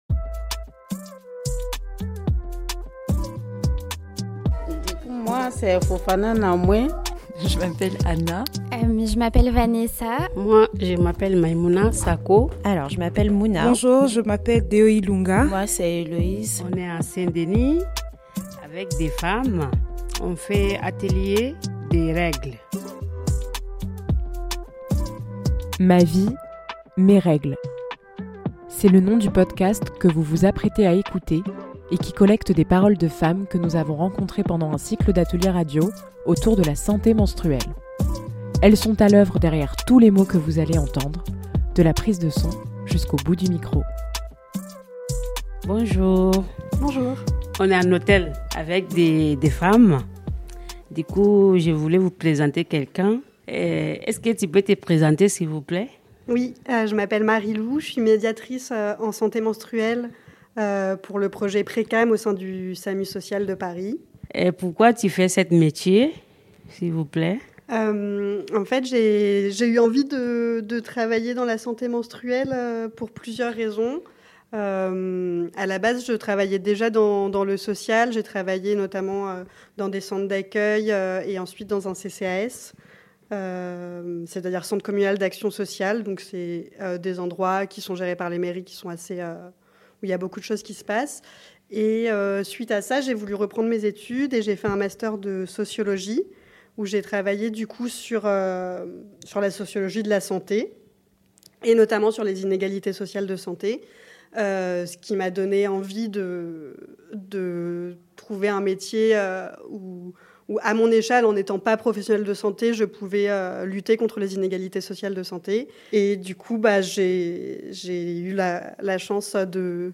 Pendant 5 semaines, nous nous sommes rendues au sein de l'hôtel social de la ville de Saint-Denis où nous avons rencontré des habitantes pour se parler d'histoires de règles. Entre initiation à la santé menstruelle et récits plus personnels, les participantes de l'atelier ont mis en son et en voix cette émission, un message adressé à toutes les personnes menstruées et toutes les personnes qui les côtoient.